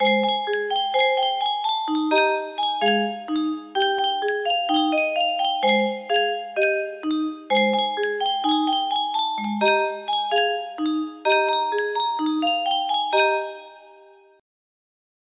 Yunsheng Music Box - Aggie War Hymn Y502 music box melody
Full range 60